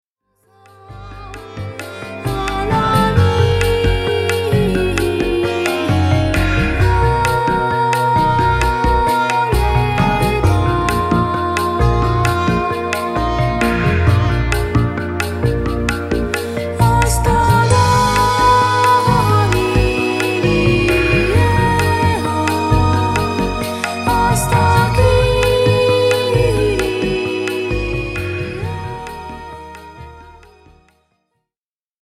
民族音楽調を中心に、様々な方向性を持った4つのボーカル・コーラス曲を収録しました。
ジャンル： World, EasyListening, NewAge